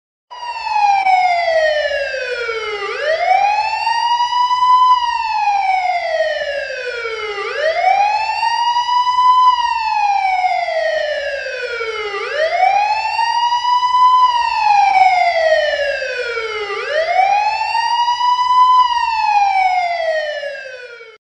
دانلود آهنگ آژیر پلیس 2 از افکت صوتی حمل و نقل
دانلود صدای آژیر پلیس 2 از ساعد نیوز با لینک مستقیم و کیفیت بالا
جلوه های صوتی